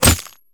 bullet_impact_glass_01.wav